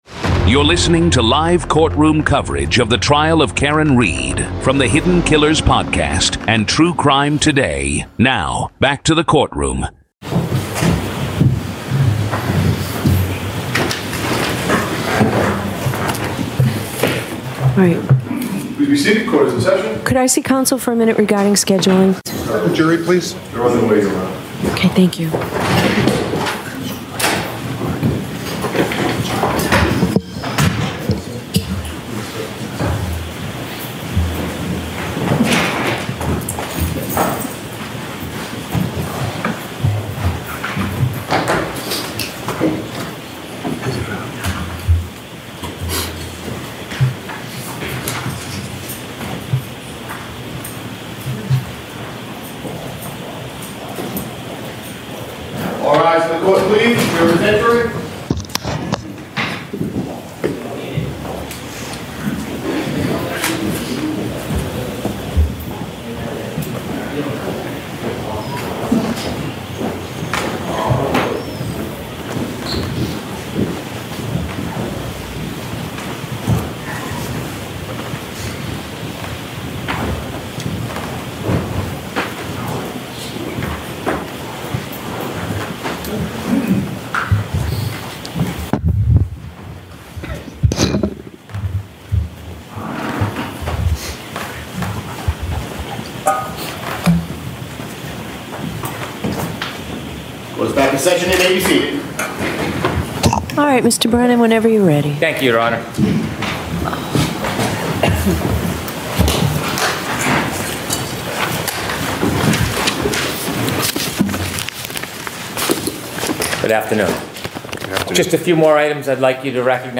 This is audio from the courtroom in the high-profile murder retrial of Karen Read in Dedham, Massachusetts.